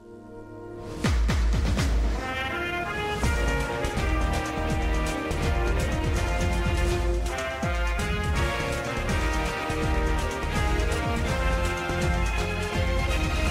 Voici 3 exemples de générique :